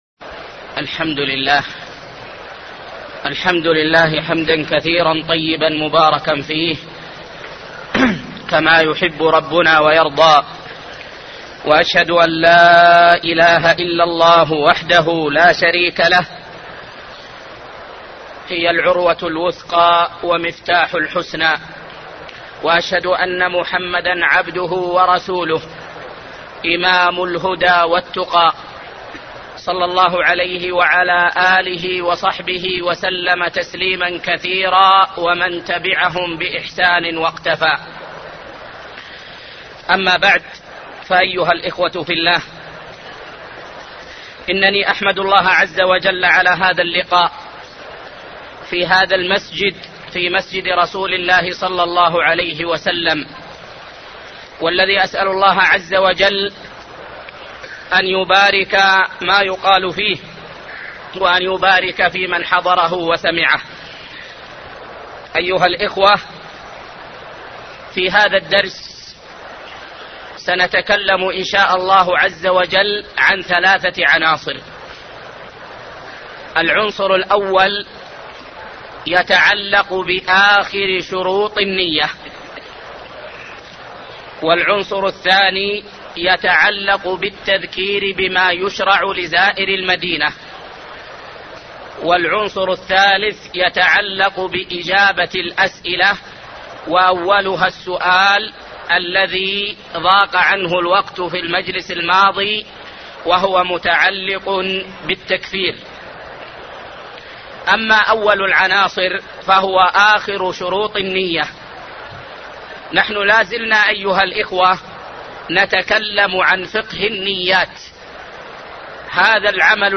الدرس السادس